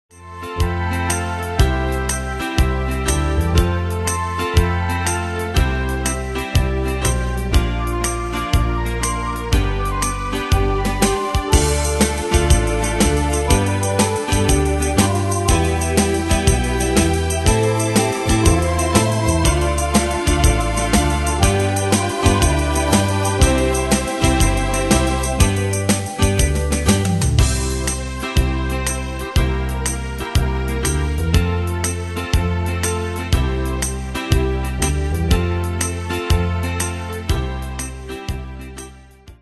Style: Retro Année/Year: 1967 Tempo: 121 Durée/Time: 3.42
Danse/Dance: Foxtrot Cat Id.